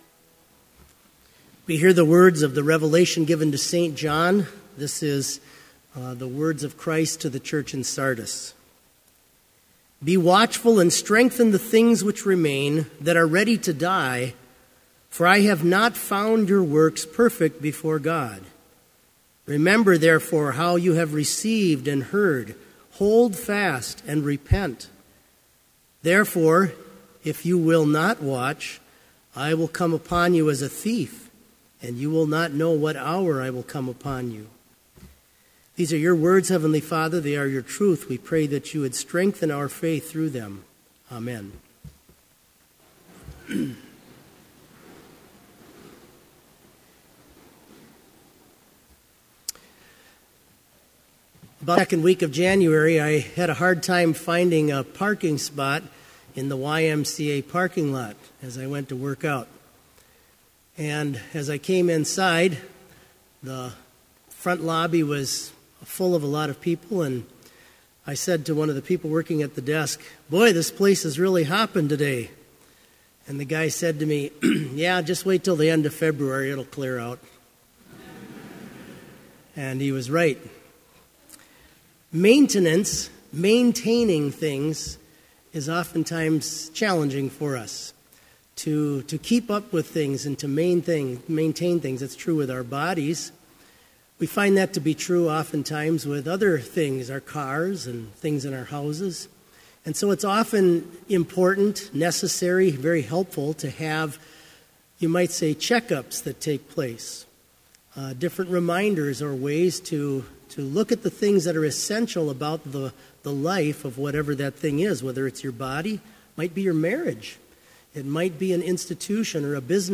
Sermon audio for Chapel - March 1, 2018
Sermon Only